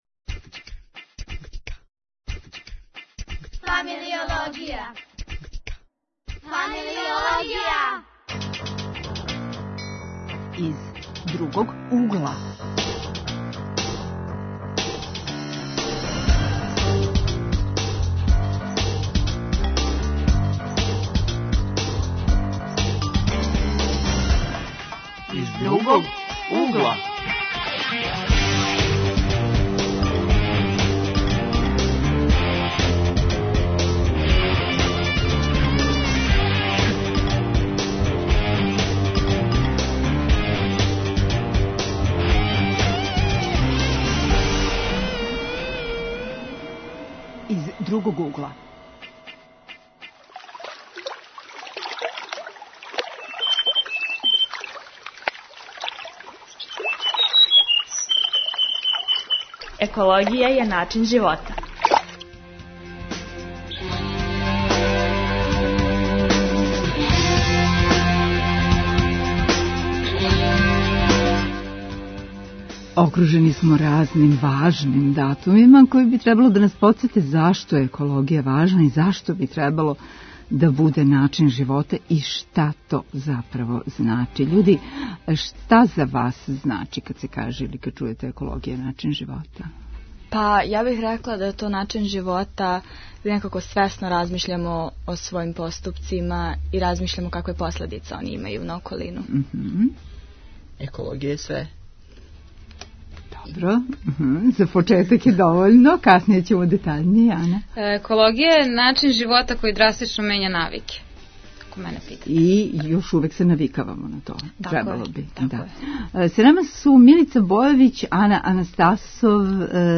Гости у студију